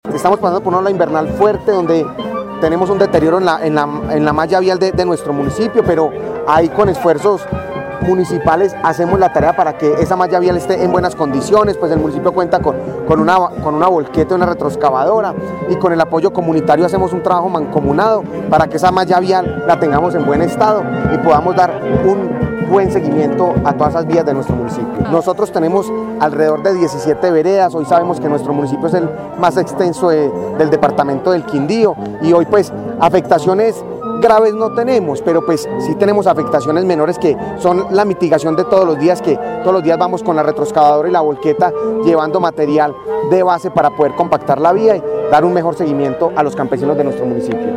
Alcalde de Salento